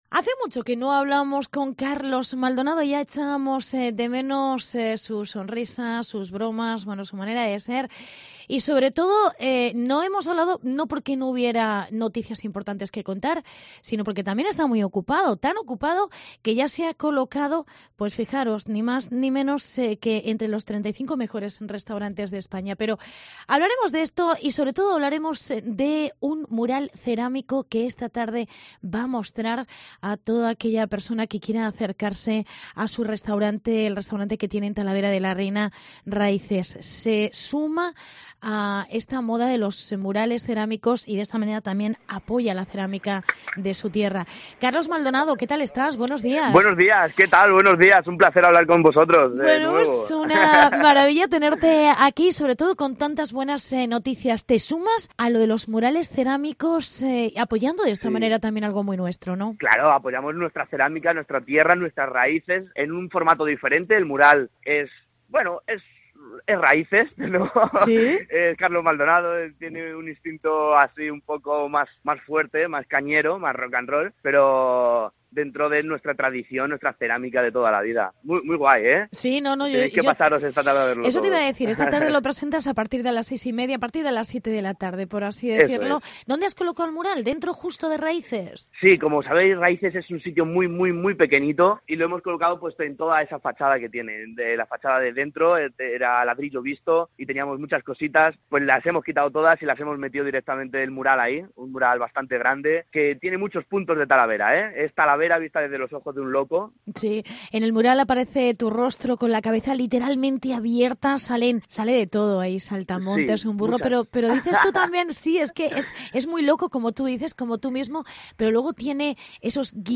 AUDIO: El chef talaverano Carlos Maldonado presenta un mural de cerámica que representa sus locuras. Entrevista